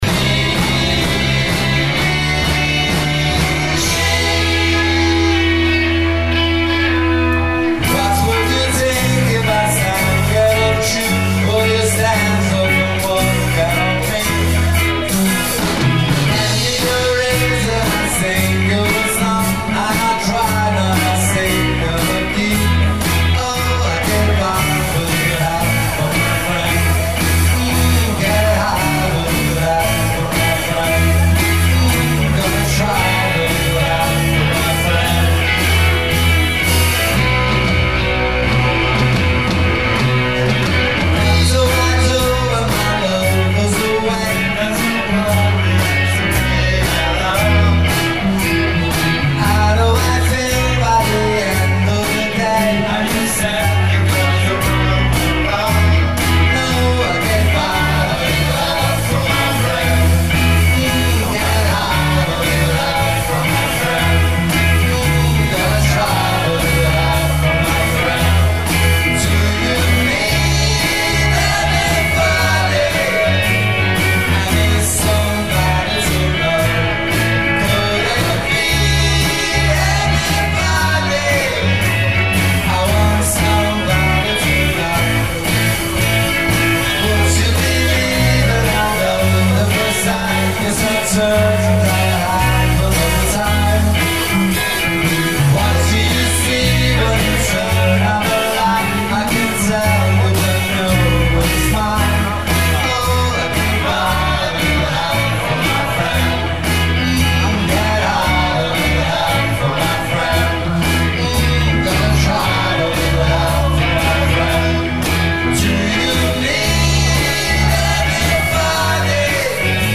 batteria e voce
chitarra
basso
voce solista
in versione live